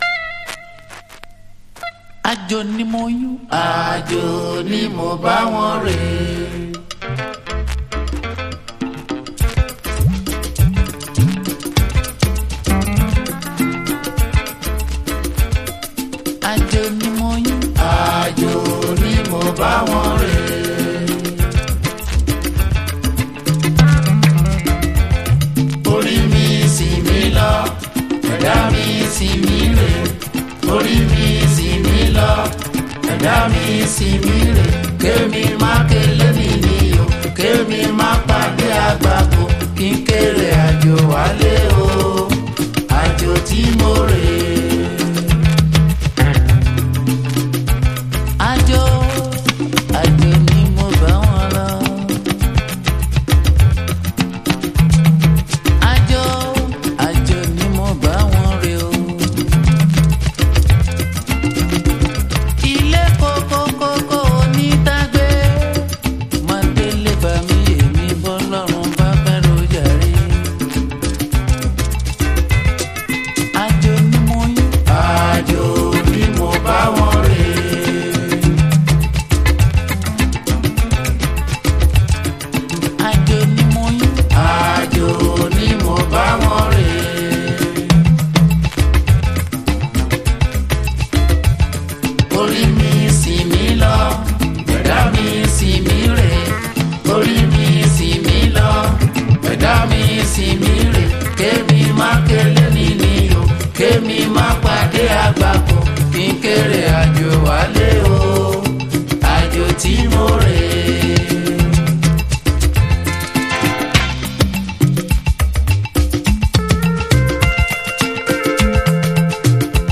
ディープ・ハウス/コズミック・ディスコ好きにも大推薦のミニマル・アフロ/ジュジュ・グルーヴ！